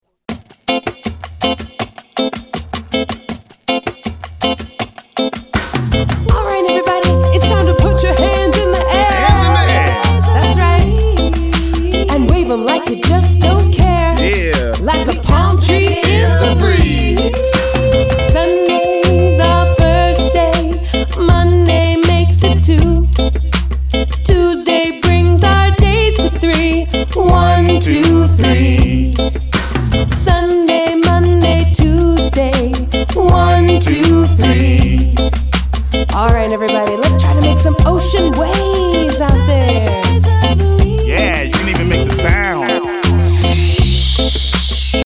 Rap and sing about counting, friendship, shapes and coins